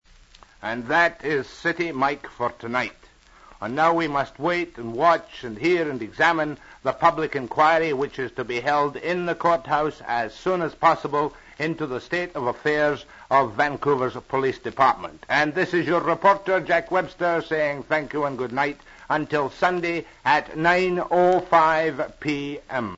Jack Webster - Click for alternate photo courtesy CKNW Jack Webster (John Edgar Webster) - Vancouver Sun reporter 1947; CJOR Vancouver 1953; Sunday Dispatch and TV Glasgow 1957; city mike and capsule comments CKNW New Westminster 1957; CKLG Vancouver 1960; talk show host CKNW 1961; CBC Vancouver correspondent; CJOR 1972; BCTV Vancouver 1979-87; Order of Canada 1988; News Hall of Fame and CAB Broadcast Hall of Fame 1989; panelist CBC-TV Front Page Challenge 1990-95.
City Mike audio circa 1955